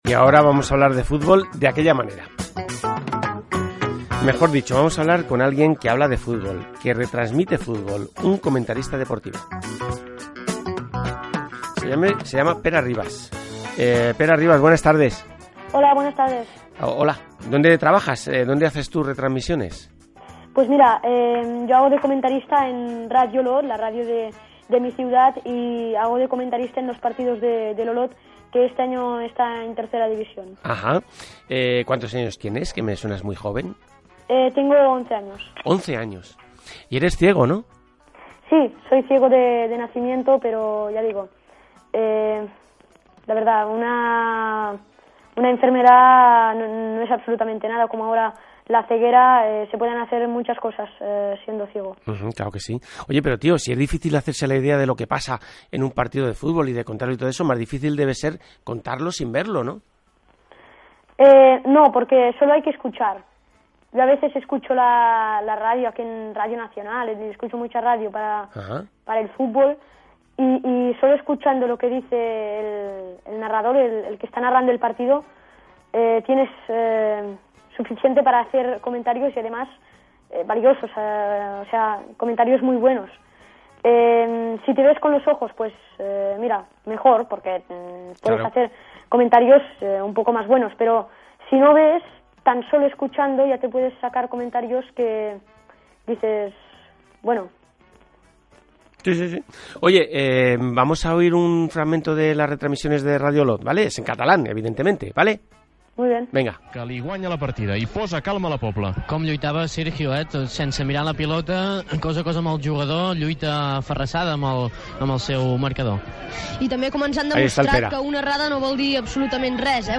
Entrevista
Gènere radiofònic Entreteniment